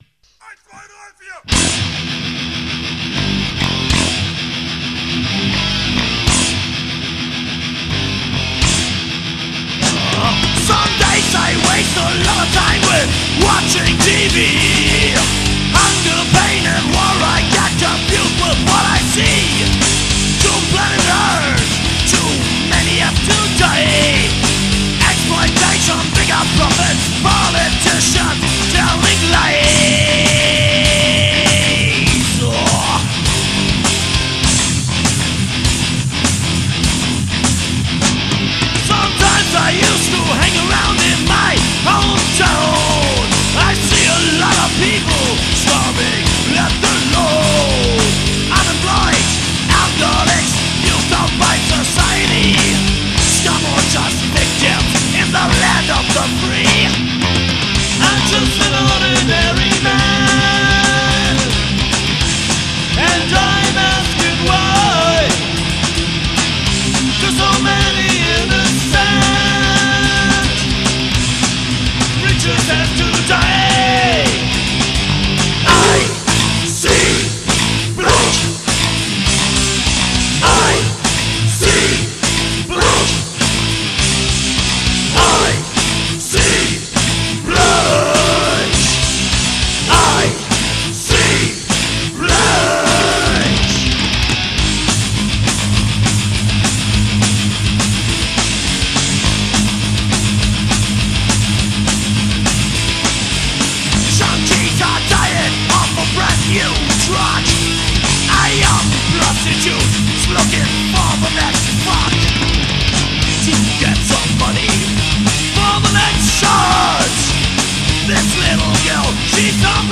8-Spur/Studio